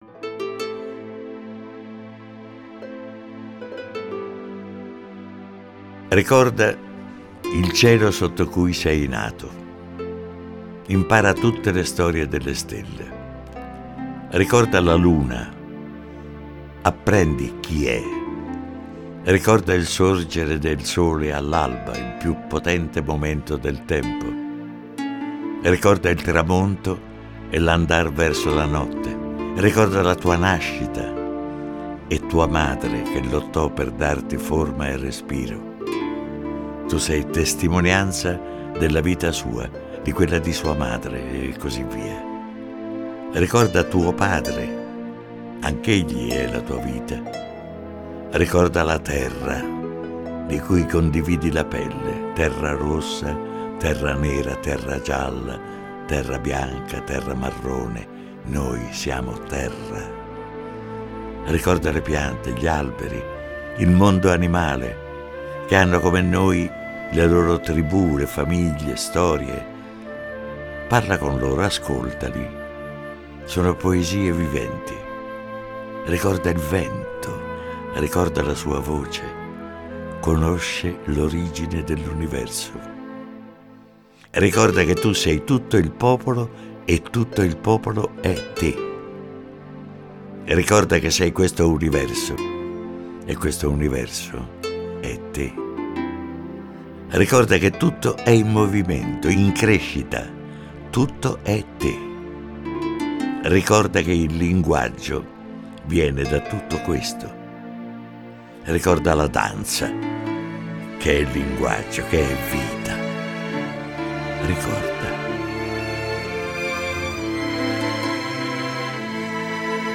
Recita Arnoldo Foà